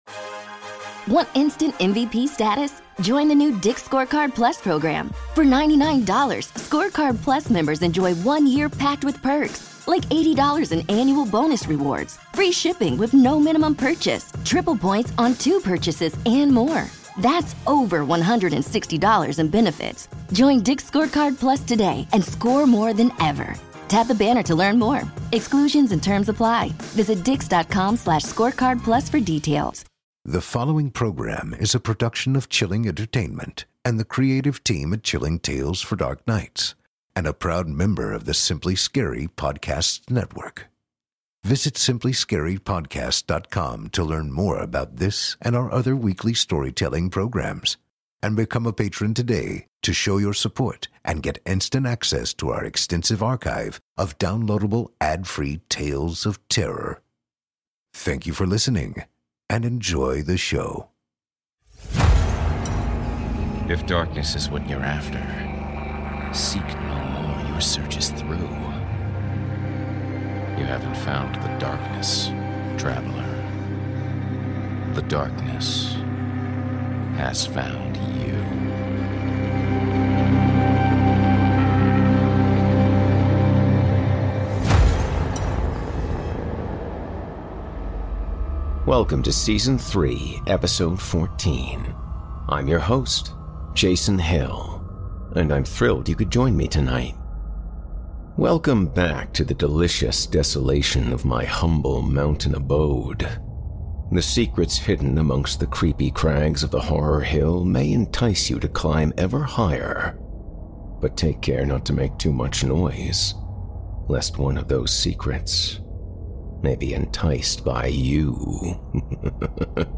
Scary Stories